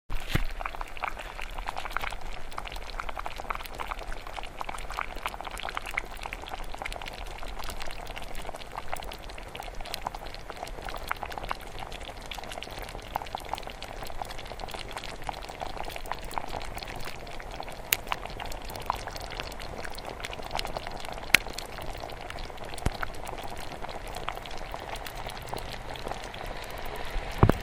Toil and trouble: lentil soup boiling on the hob